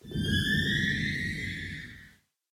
Minecraft Version Minecraft Version latest Latest Release | Latest Snapshot latest / assets / minecraft / sounds / ambient / cave / cave10.ogg Compare With Compare With Latest Release | Latest Snapshot
cave10.ogg